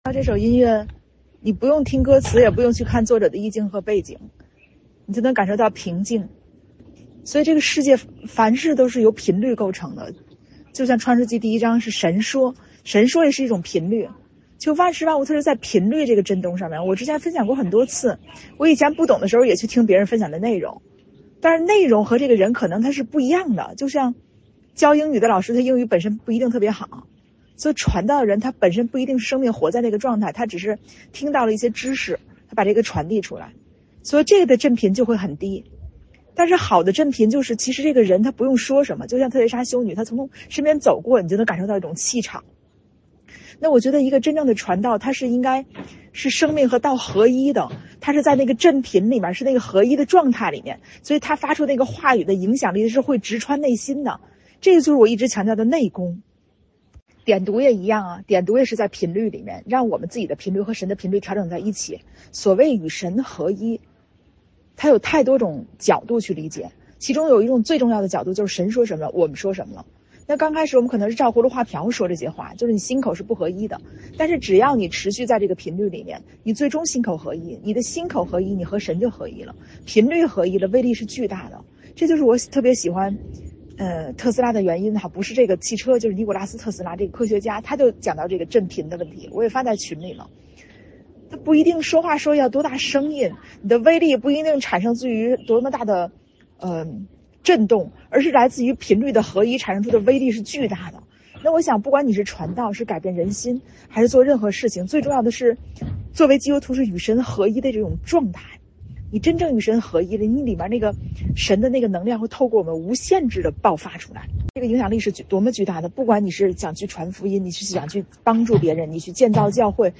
音乐：肖邦 夜曲